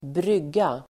Uttal: [²br'yg:a]